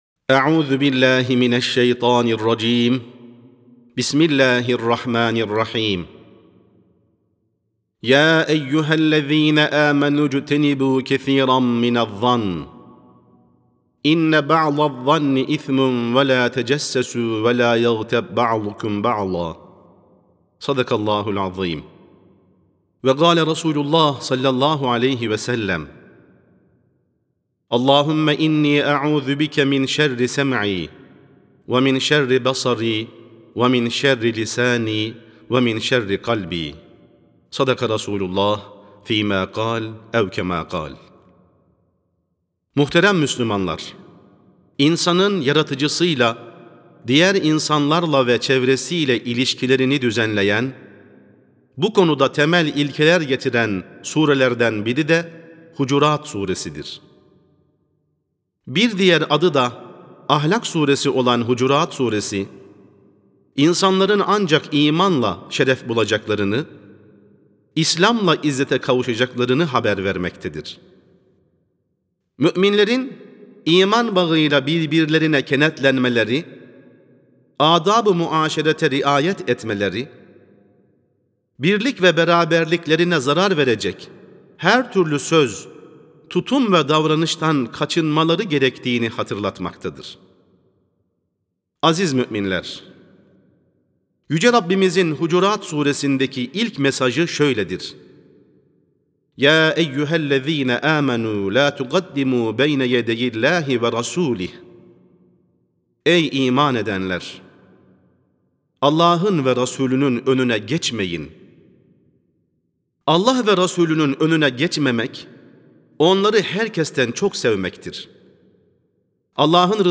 18.04.2025 Cuma Hutbesi: Hucurât Sûresi: Erdemli Bir Toplumun İnşası (Sesli Hutbe, Türkçe, İngilizce, Rusça, İtalyanca, Arapça, Almanca, Fransızca, İspanyolca)
Sesli Hutbe (Hucurât Sûresi, Erdemli Bir Toplumun İnşası).mp3